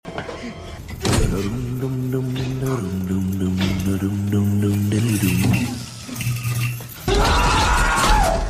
Foxy Dum Dum Dum Sound sound effects free download